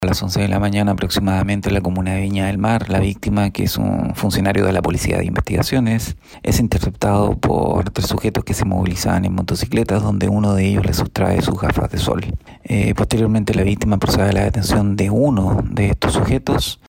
En este sentido, el fiscal de turno regional de Instrucción y Flagrancia, Andrés Gallardo, confirmó lo anterior.